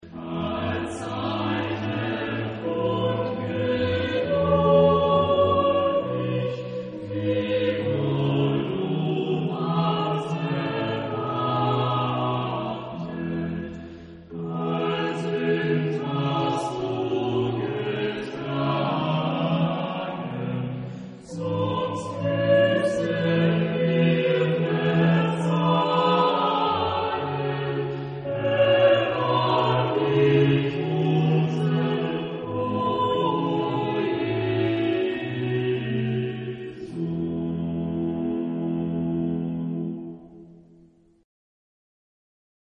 Genre-Style-Forme : Choral ; Sacré
Type de choeur : SATB  (4 voix mixtes )
Instruments : Orgue (1) ad lib
Tonalité : fa majeur